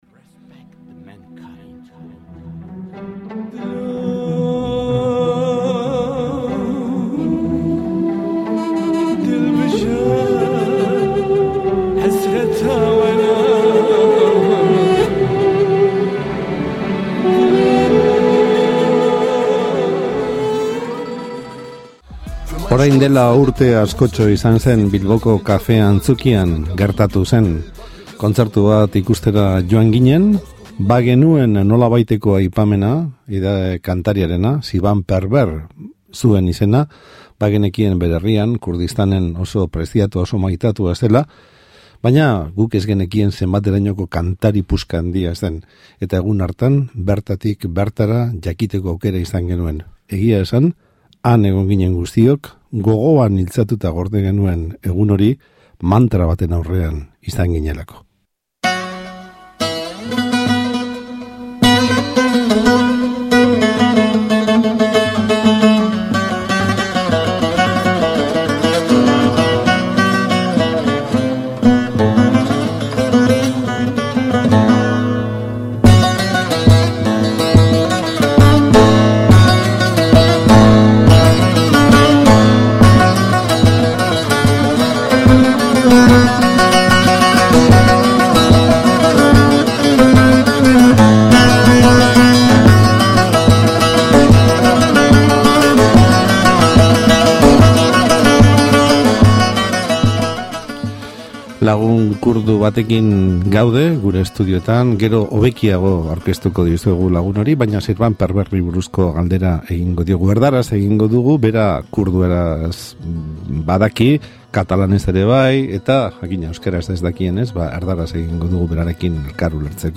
Bertan aurkitu zuen errefuxiatuen ihesaldia deskribatu digu, hitzez zein kamararekin, dokumental honetan. Han aurkitu zuen hondamendiak eragindako sentimenduez, emakumeen rolaz eta beste hainbeste konturen gainean ere jardun dugu solasaldi interesgarri honetan.